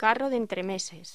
Locución: Carro de entremeses
voz